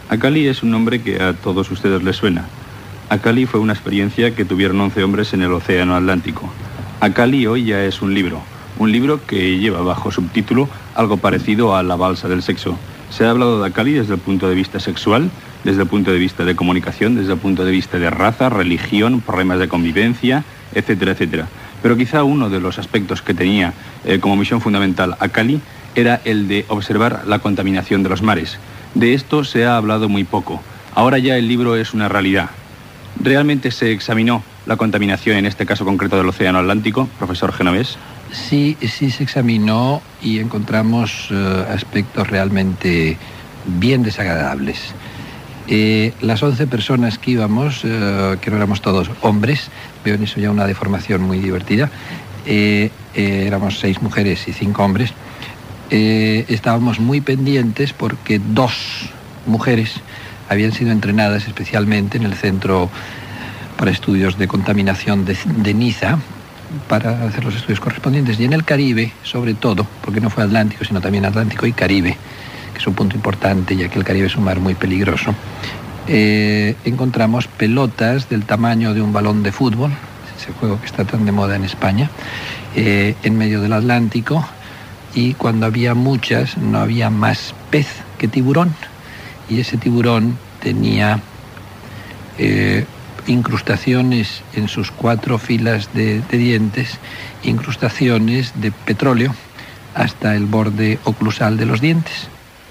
Entrevista a Santiago Genovés sobre el llibre que recull el trajecte en la balsa "Acali" de Las Palmas al Carib, passant per Barcelona. Parla de la contaminació del mar.
Informatiu